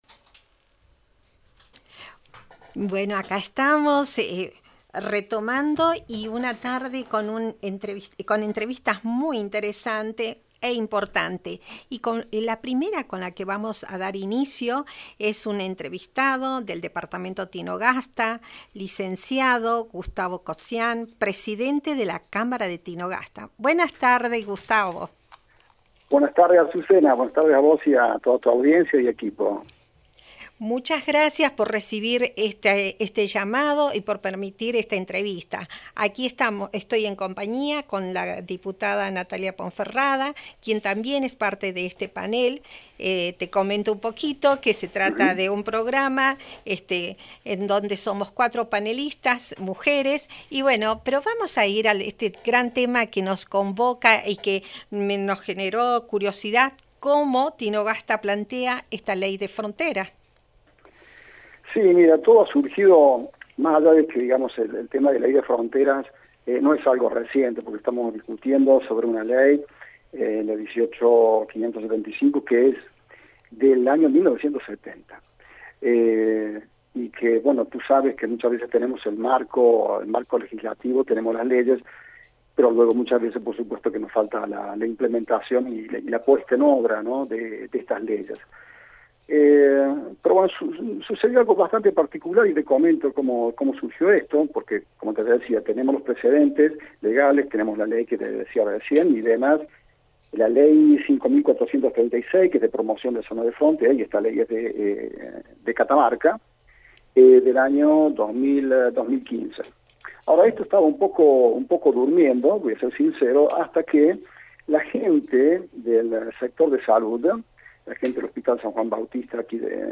En el programa La SArtén por el Mango, entrevistaron